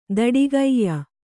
♪ daḍigaiya